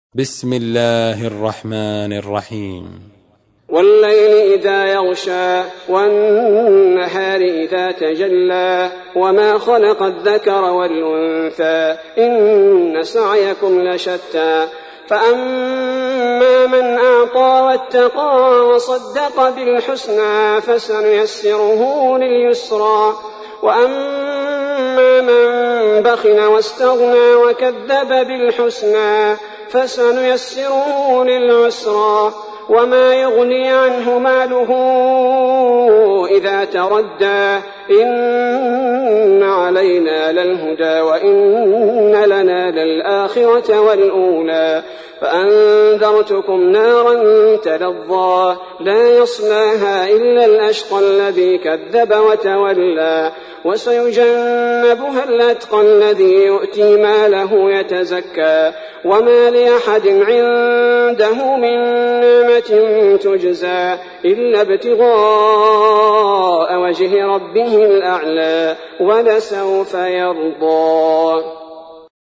تحميل : 92. سورة الليل / القارئ عبد البارئ الثبيتي / القرآن الكريم / موقع يا حسين